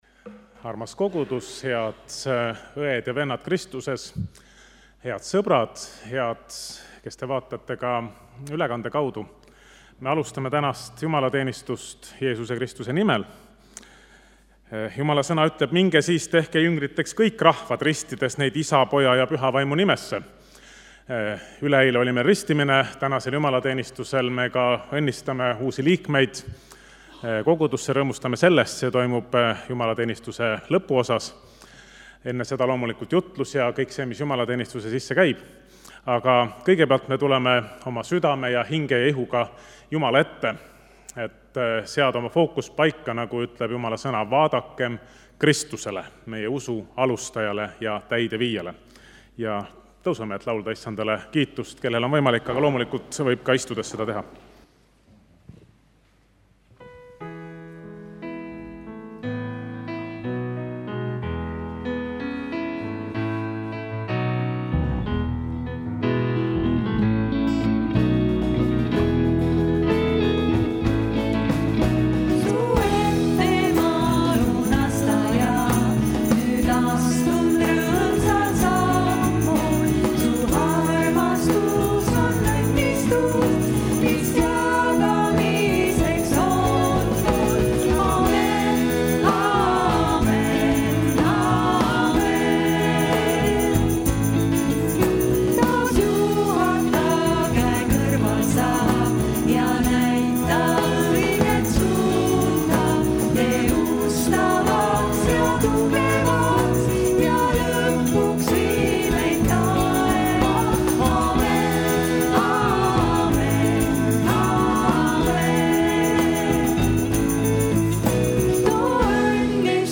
Tunnistus noortelaagrist: Kolgata noored
Jutlus